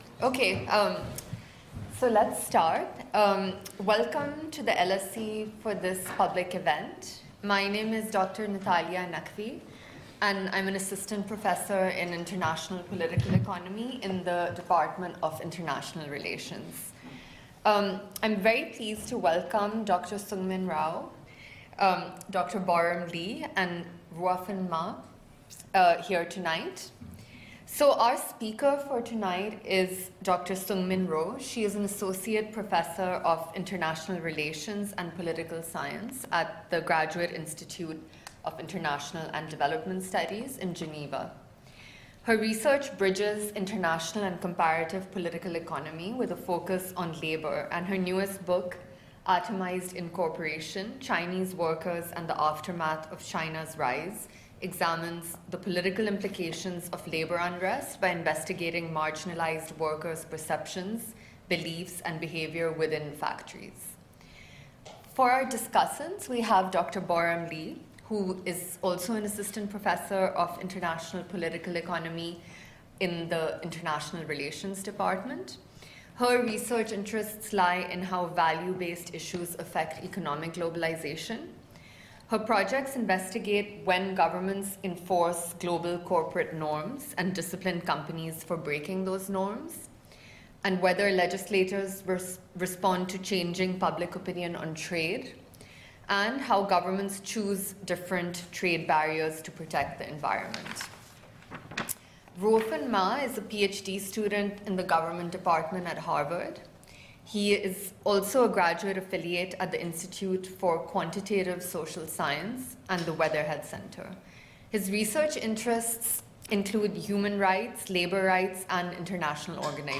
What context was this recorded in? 6.30pm Wed 19 March 2025 | Atomized Incorporation: Chinese workers and the aftermath of China's rise | Free public event at LSE